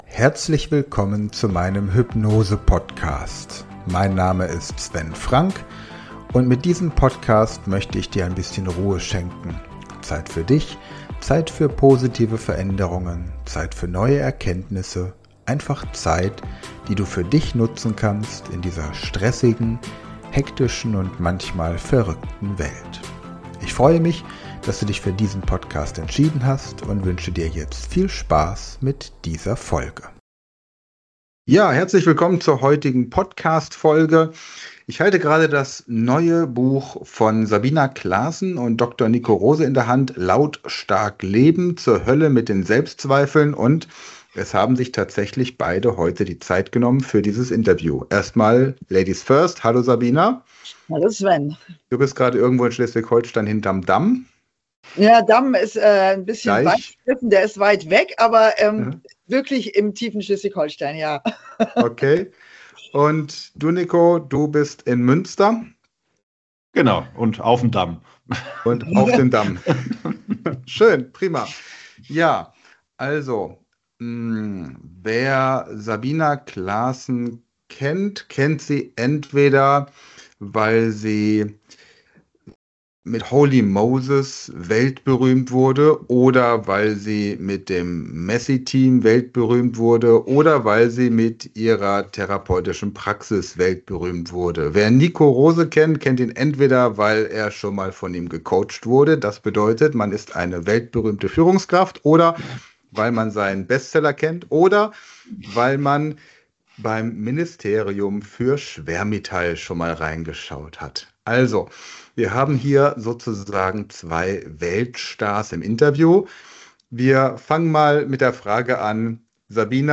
Also wir haben hier sozusagen 2 Weltstars im Interview.